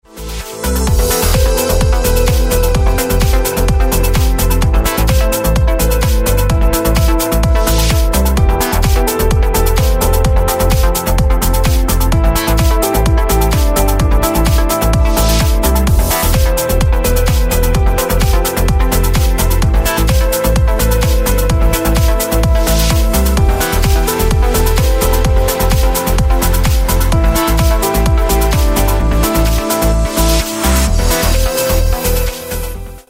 • Качество: 128, Stereo
громкие
EDM
электронная музыка
без слов
progressive house
progressive trance